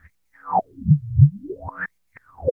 23 REVERSE-L.wav